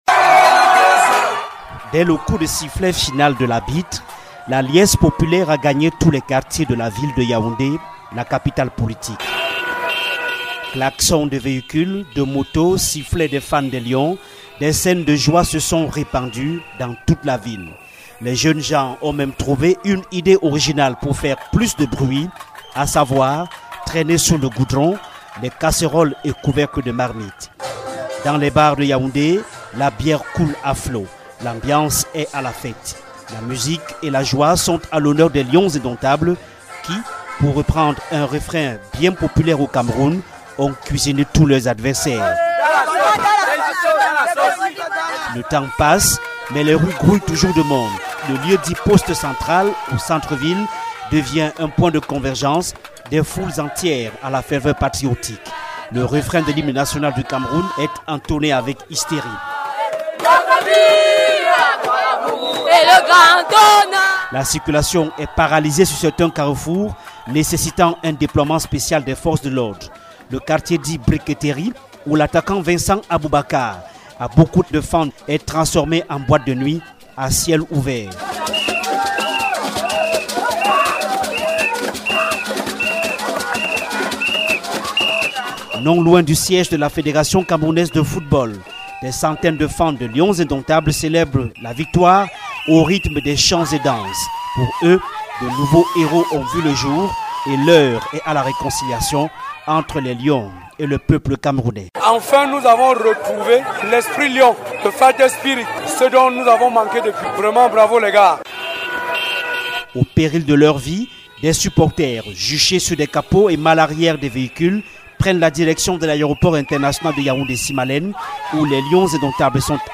Scènes de liesse à Yaoundé après la victoire du Cameroun